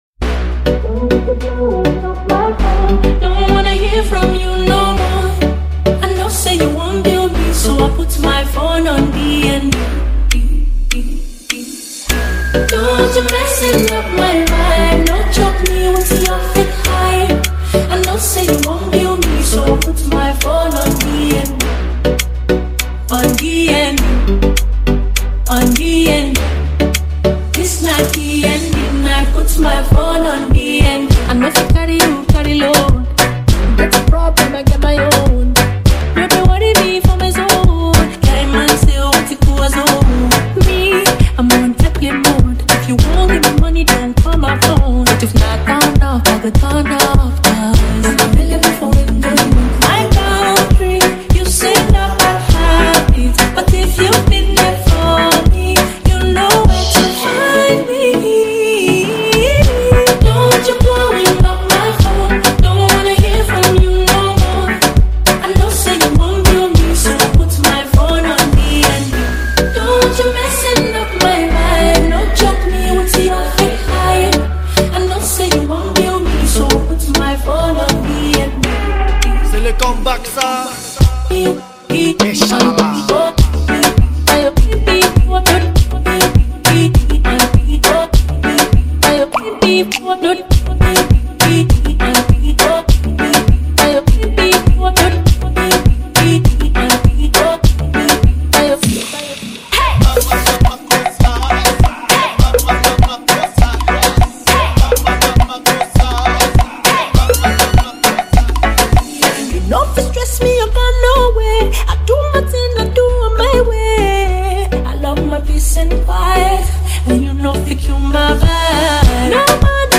Well renowned Nigerian female artist and songwriter
thrilling new gbedu song